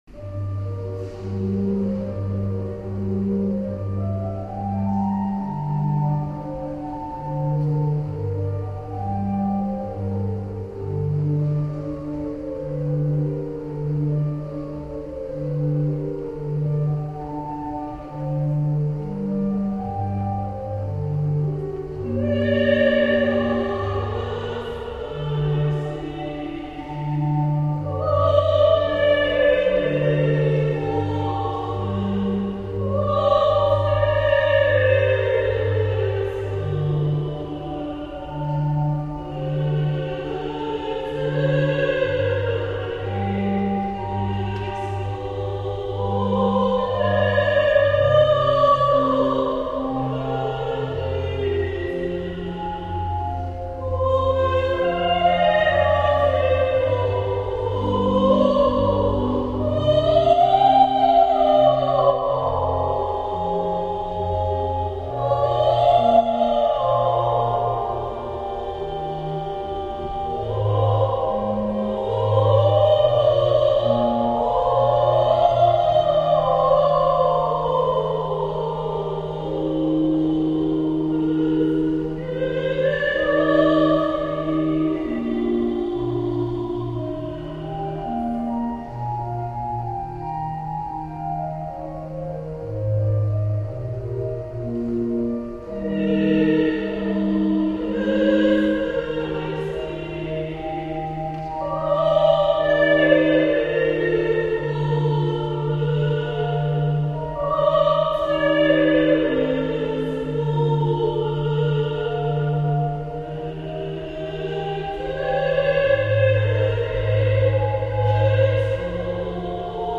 Posłuchaj mnie - utwory wykonane z towarzyszeniem organów